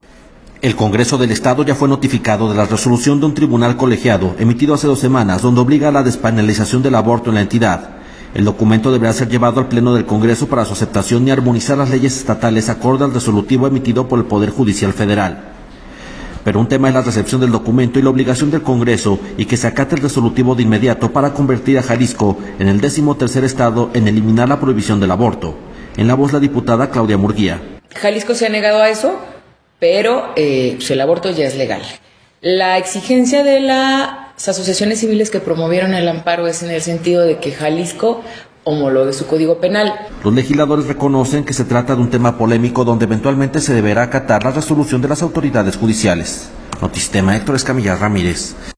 En la voz la diputada Claudia Murguía.